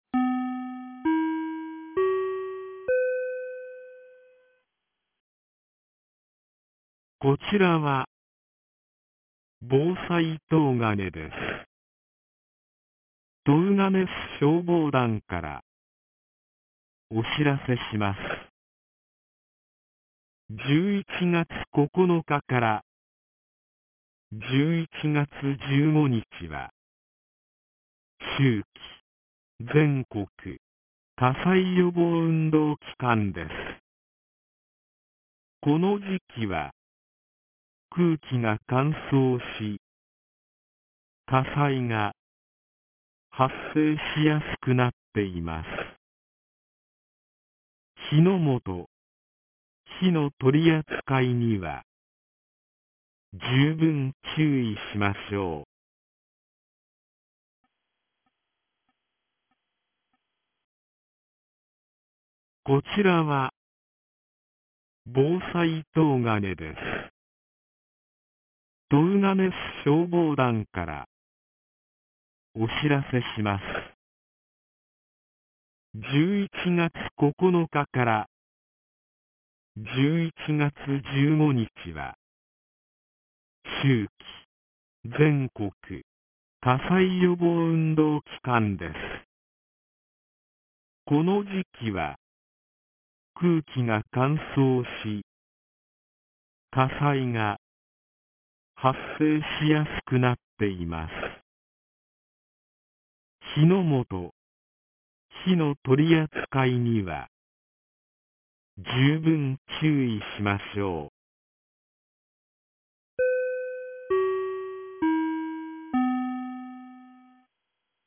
2025年11月09日 09時31分に、東金市より防災行政無線の放送を行いました。